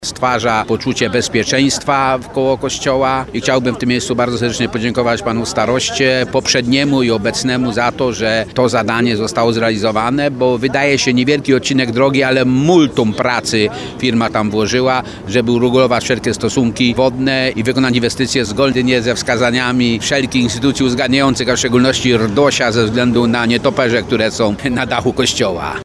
– Kierowcy mogą już korzystać z nowego odcinka drogi, który porządkuje ruch w tej okolicy – mówi Stanisław Kiełbasa, wójt gminy Nawojowa.